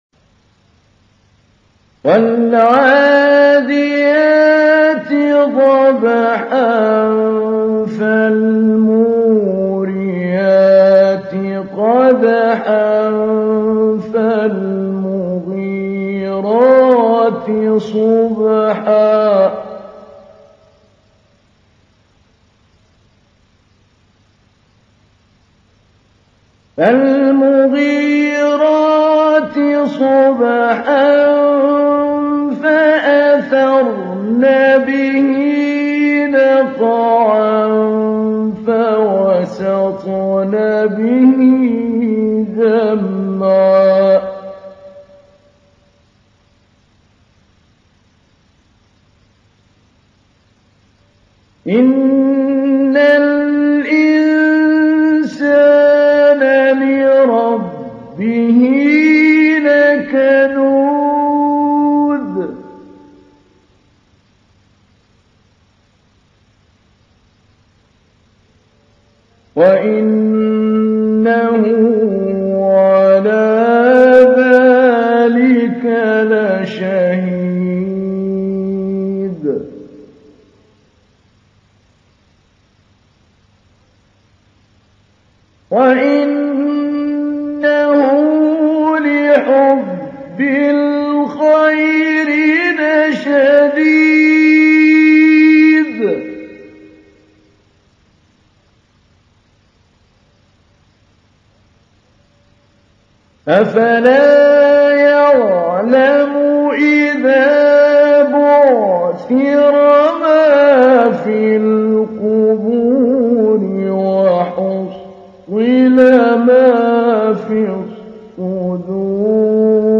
تحميل : 100. سورة العاديات / القارئ محمود علي البنا / القرآن الكريم / موقع يا حسين